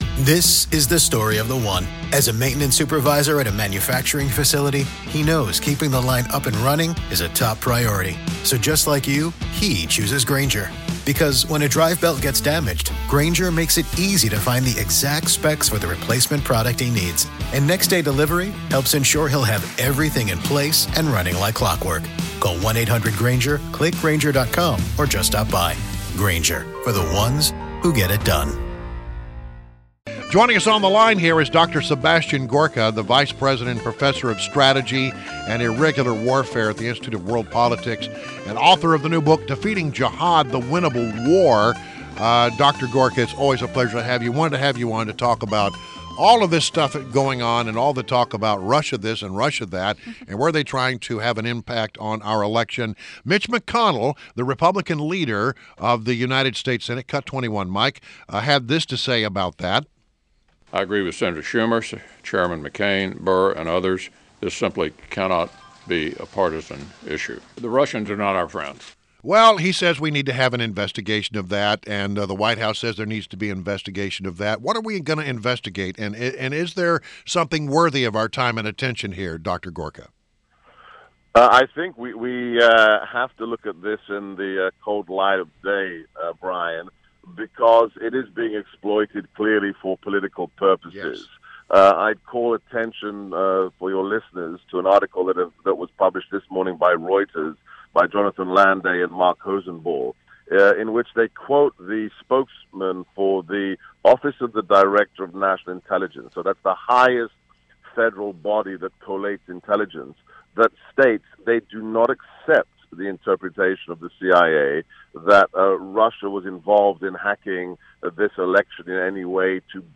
WMAL Interview - DR. SEBASTIAN GORKA - 12.13.16